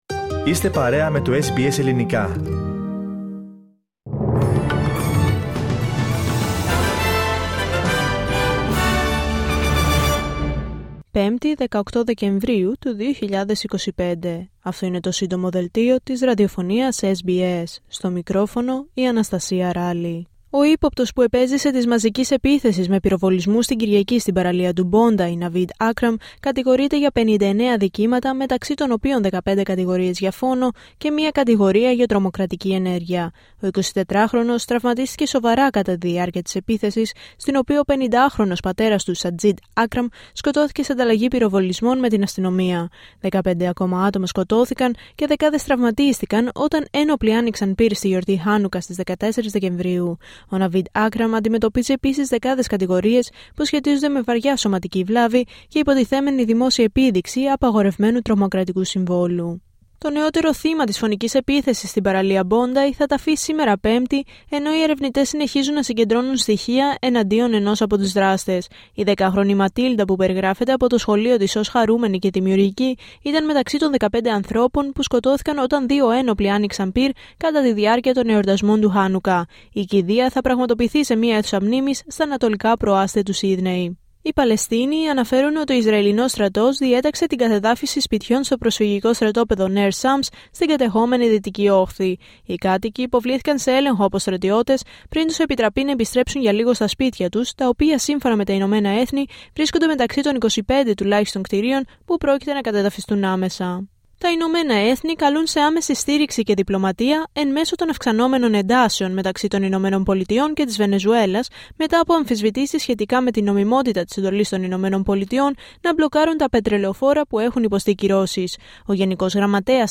H επικαιρότητα έως αυτή την ώρα στην Αυστραλία, την Ελλάδα, την Κύπρο και τον κόσμο στο Σύντομο Δελτίο Ειδήσεων της Πέμπτης 18 Δεκεμβρίου 2025.